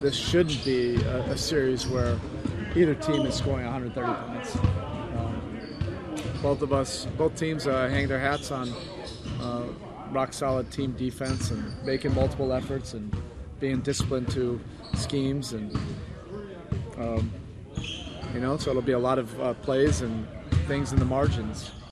Miami Heat Head Coach Erik Spoelstra speaks on the series.